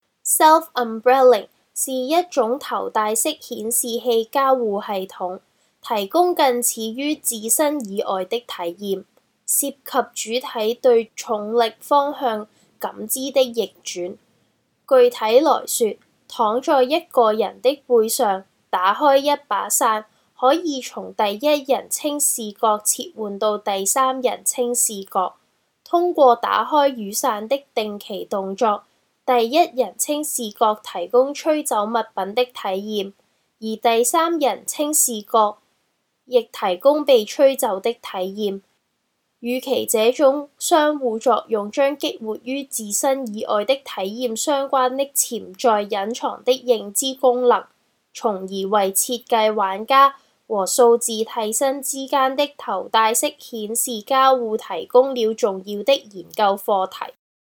The Audio Guides are the voice recordings of the Virtual & Augmented Reality (VR/AR) contributions in different languages!
Audio Guides